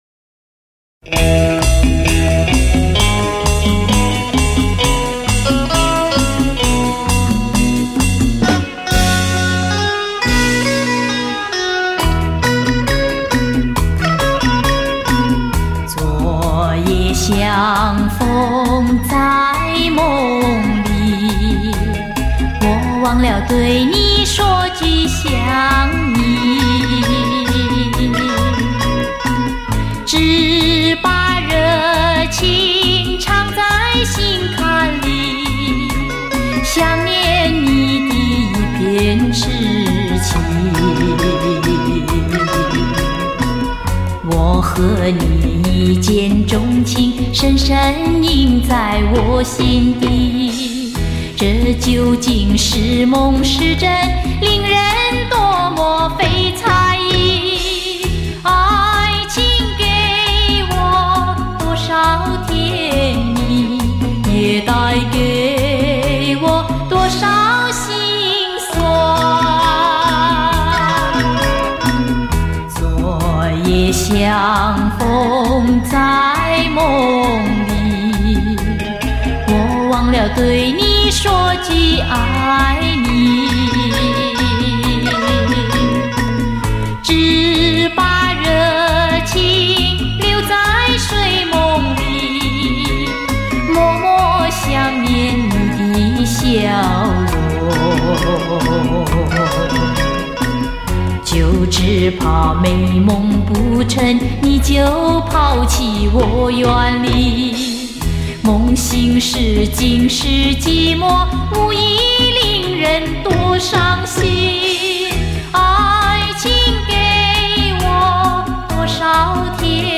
数码调音录制